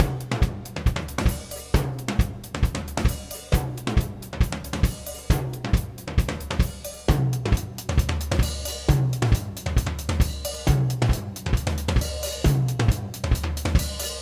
マイク９本録りしたドラムの処理
先日マイク９本で録音したドラムのデータです。
・ノイズ処理。さすがにマイク９本も立てると静かな時にはちょっと気になります。
さらに、ゲートを通した後にインサートのコンプをかけると、音が締まった感じがします。
下のデータは、これまで通りＢＤ、ＳＤ、オーバーヘッド（ステレオ）でゲートをかけない場合、９トラックでゲートをかけた場合、と続けて入れました。